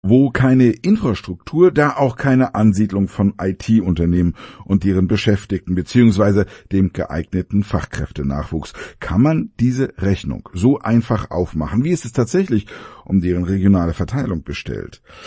male_energic.mp3